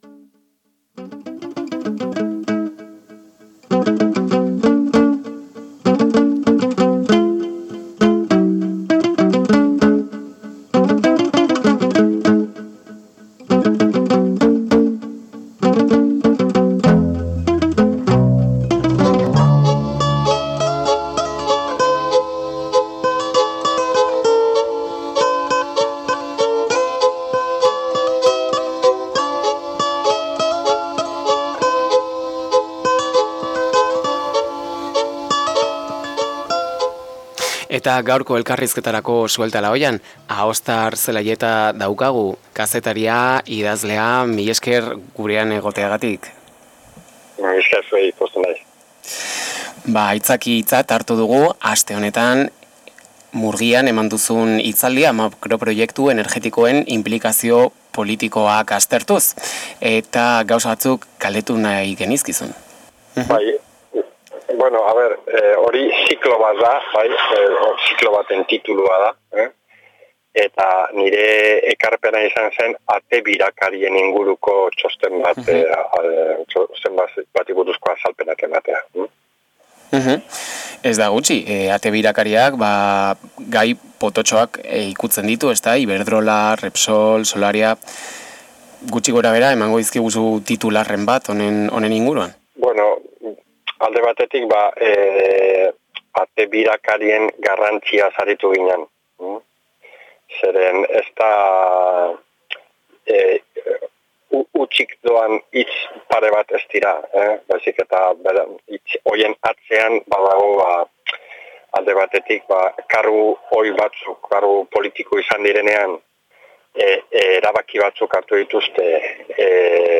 Ondoren, elkarrizketa osorik: